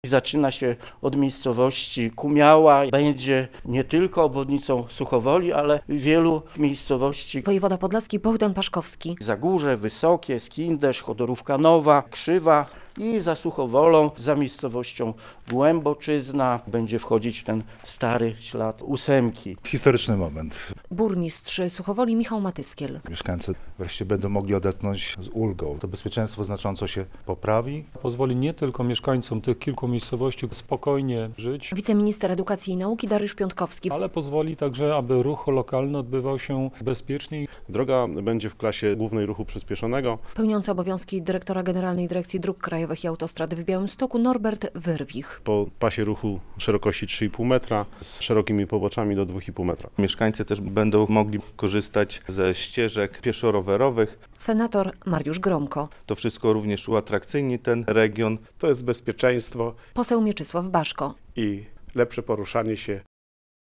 Wojewoda podlaski wydał pozwolenie na budowę obwodnicy Suchowoli - relacja